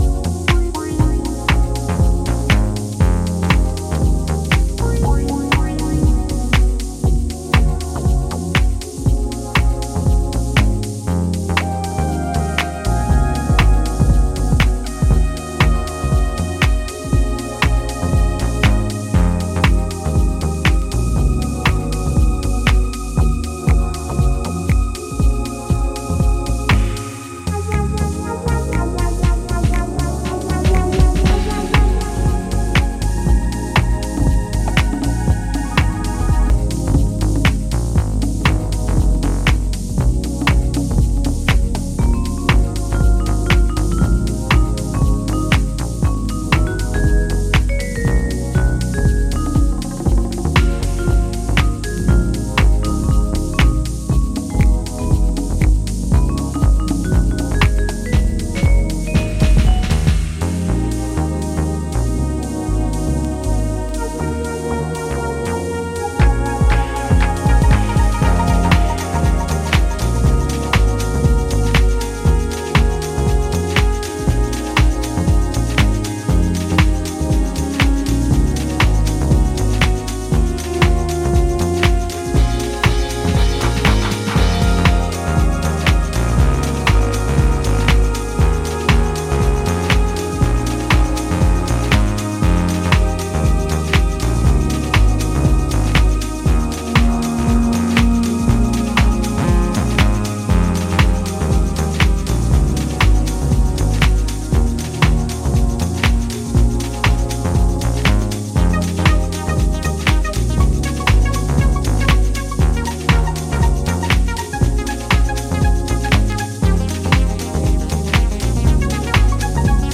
a slow-paced hip-hop kinda cut
Gritty beats, sexy melodies, and good vibes.